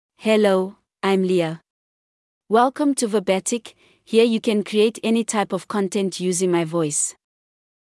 FemaleEnglish (South Africa)
Leah — Female English AI voice
Leah is a female AI voice for English (South Africa).
Voice sample
Listen to Leah's female English voice.
Leah delivers clear pronunciation with authentic South Africa English intonation, making your content sound professionally produced.